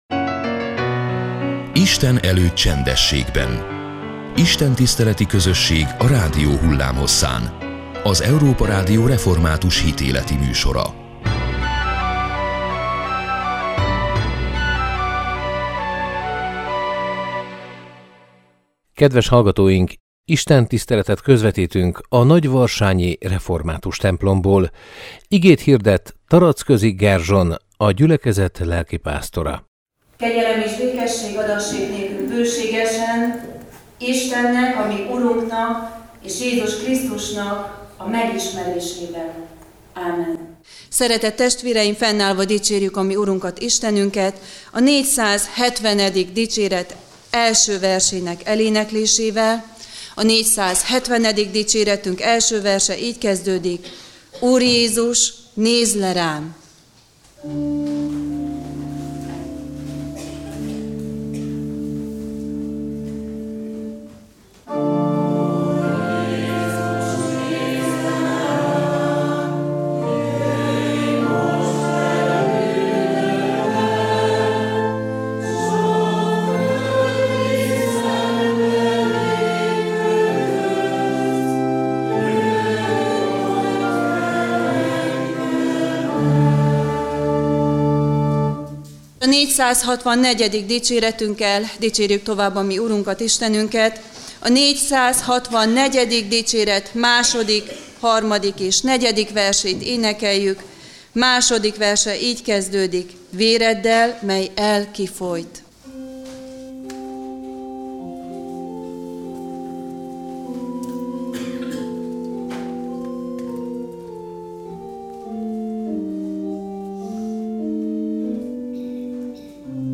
istentisztelet